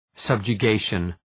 Shkrimi fonetik{,sʌbdʒʋ’geıʃən}